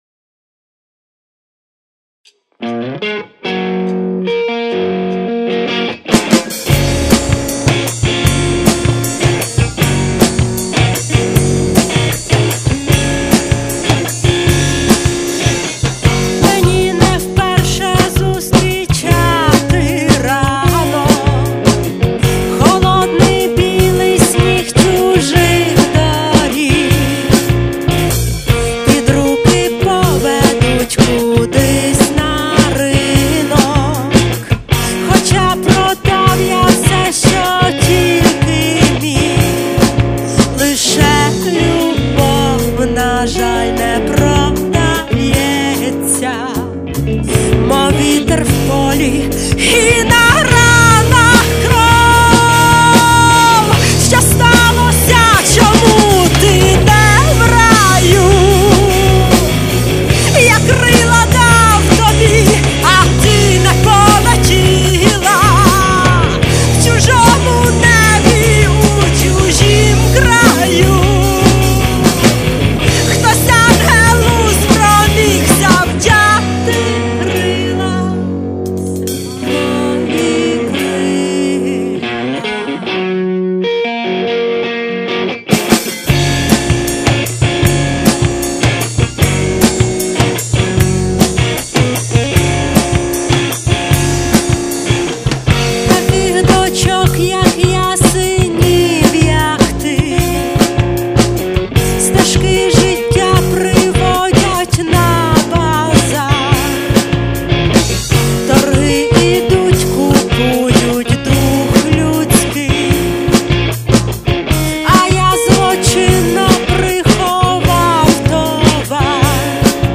Пісні про кохання, ліричні